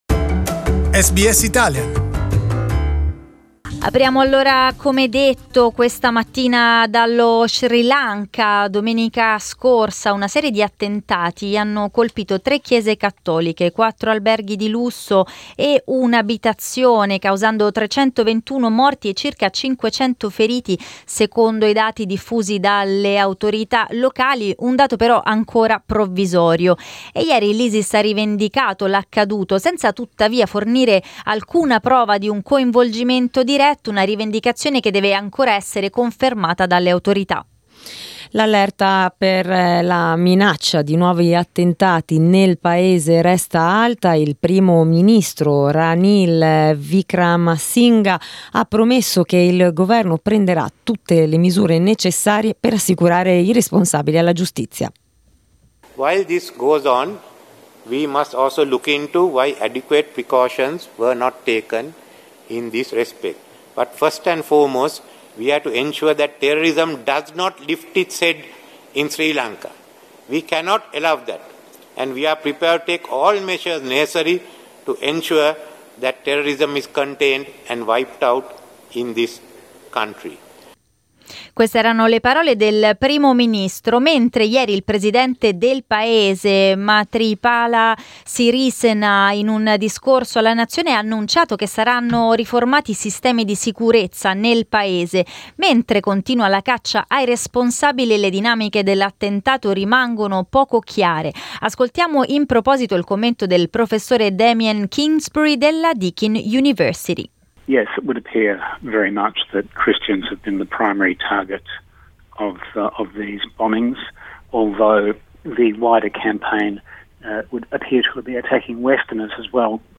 South-Asia correspondent